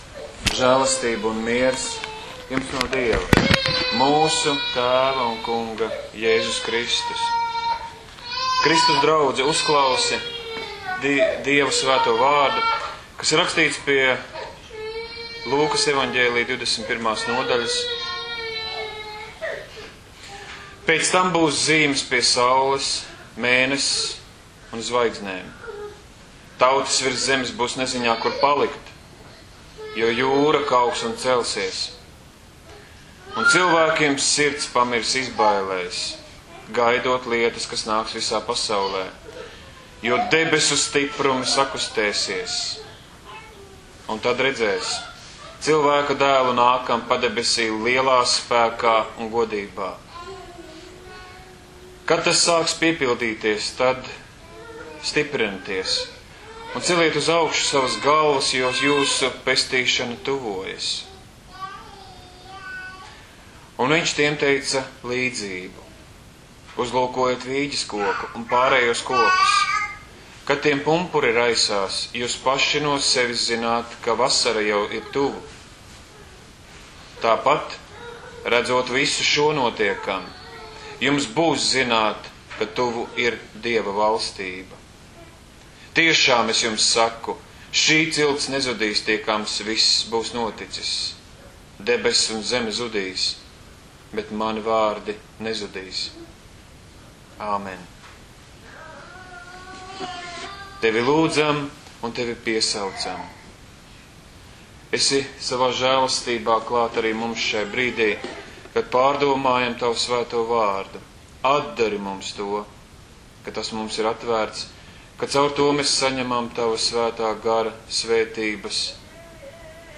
Audio sprediķi 2013./14. gads